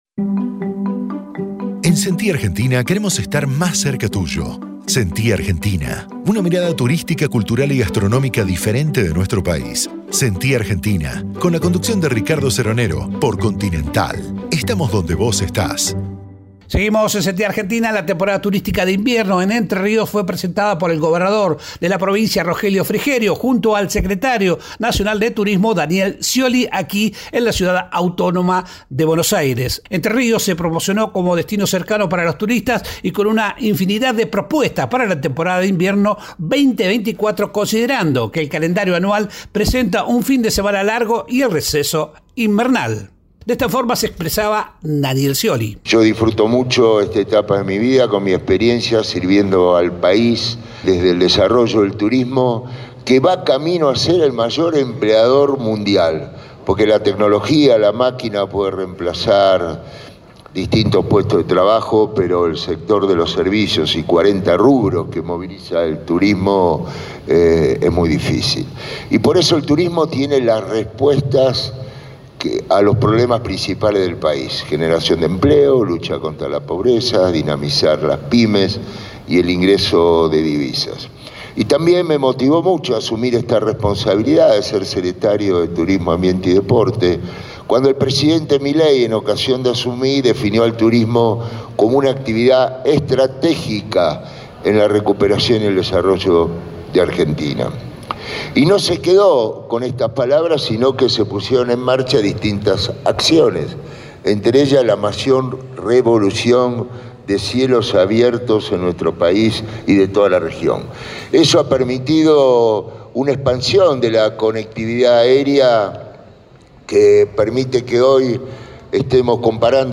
El secretario de Turismo, Ambiente y Deportes de la Nación, Daniel Scioli, en AM 590 Radio Continental.